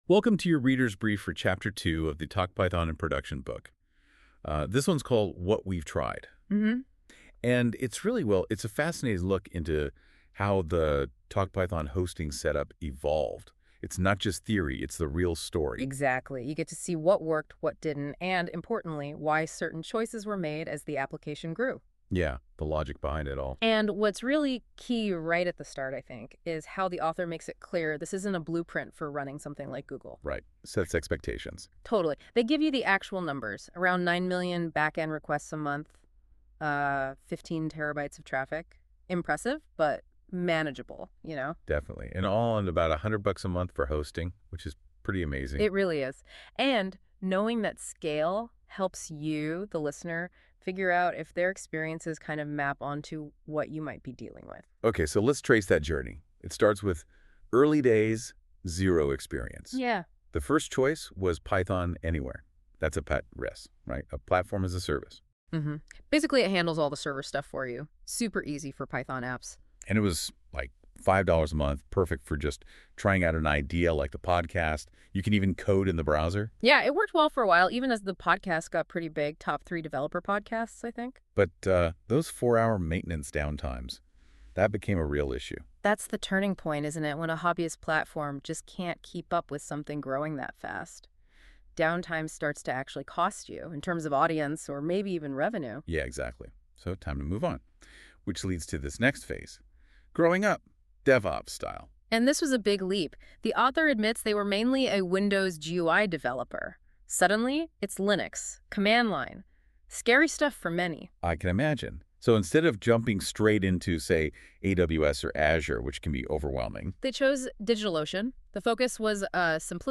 The book includes a total of 1 hour and 20 minutes of short 2 to 4 minute conversations that bookend each chapter.
They complement the code-heavy text without trying to be a word-for-word audiobook; expect the occasional quirky acronym.